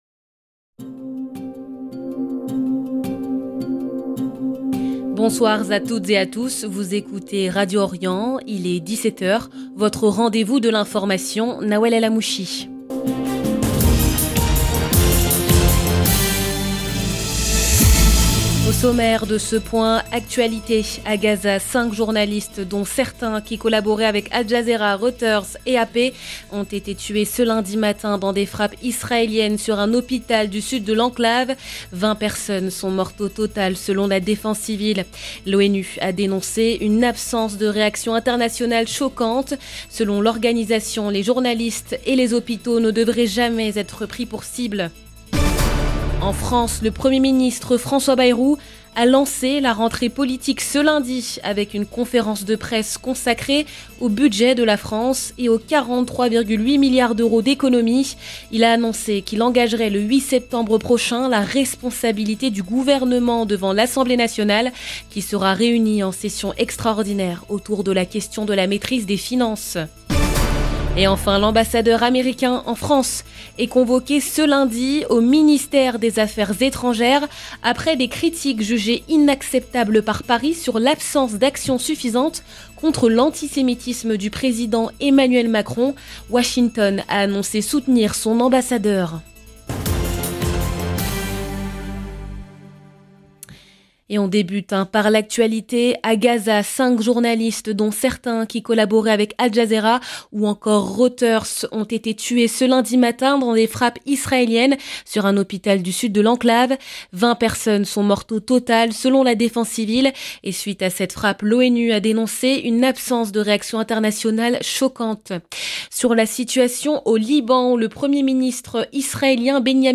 Magazine d'information de 17H du 25 août 2025